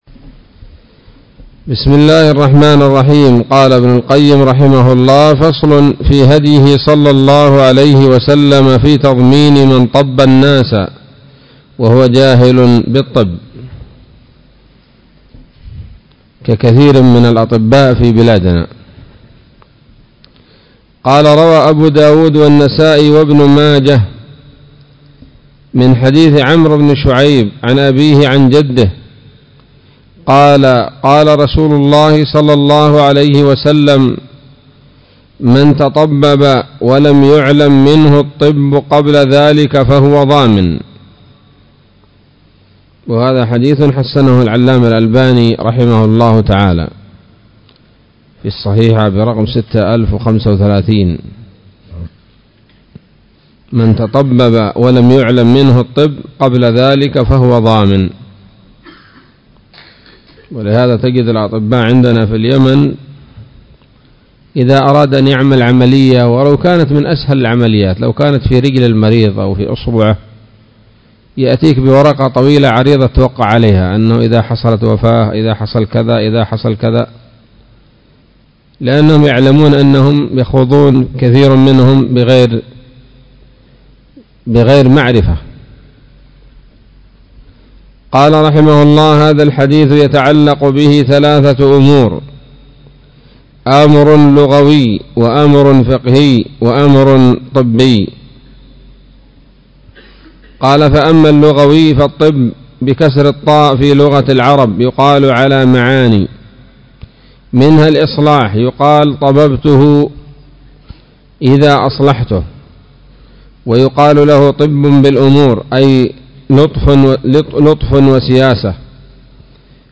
الدرس الثامن والثلاثون من كتاب الطب النبوي لابن القيم